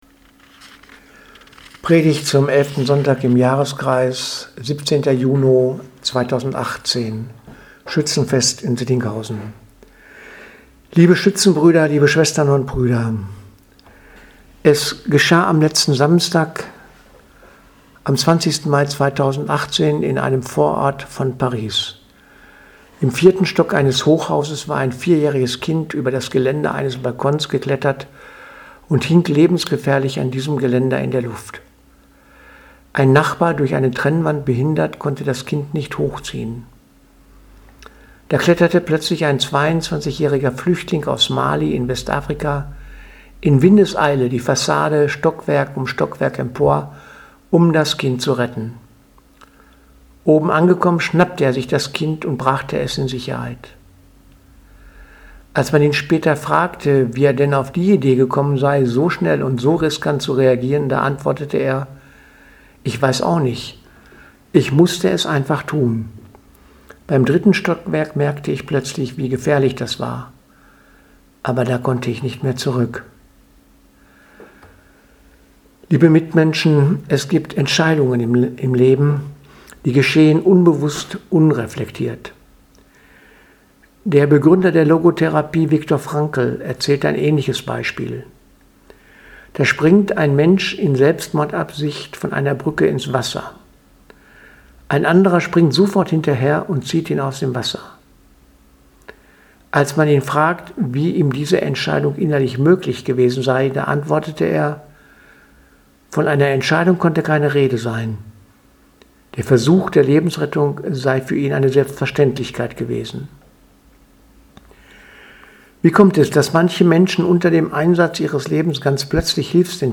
Predigt vom 15.06.2018 Schützenfest